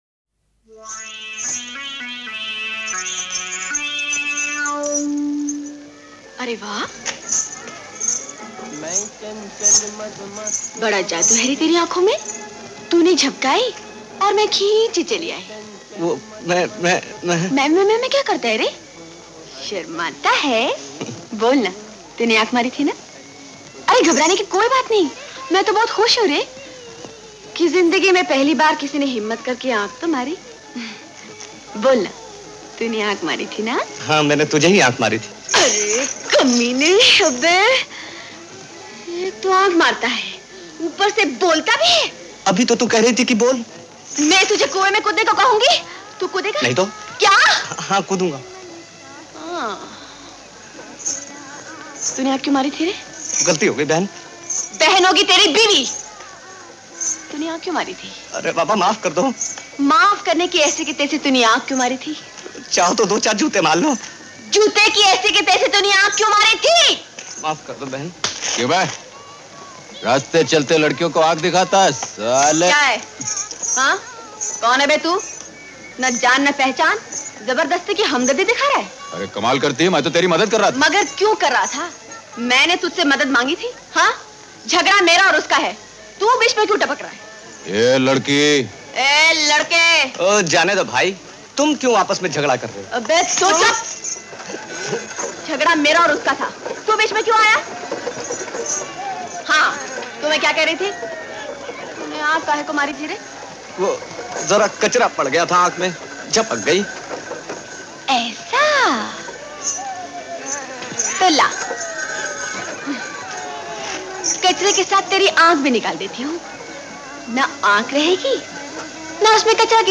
[Artist: Dialogue ]